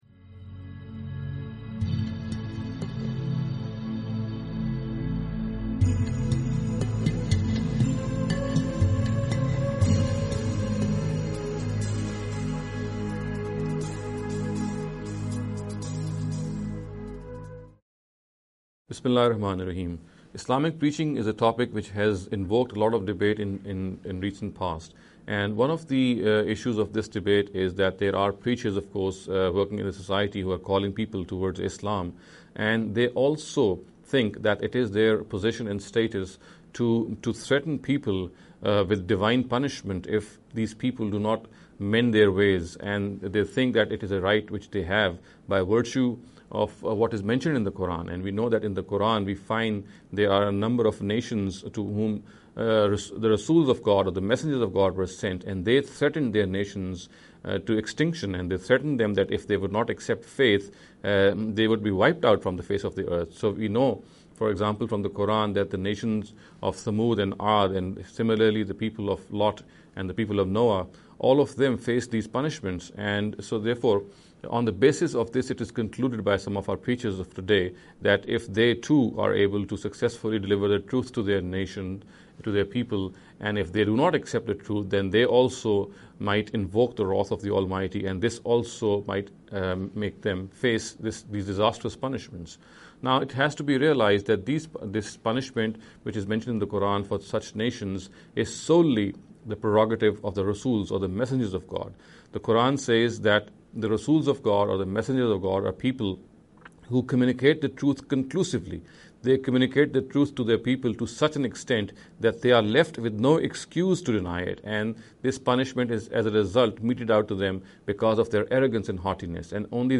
This lecture series will deal with some misconception regarding the Preaching Islam.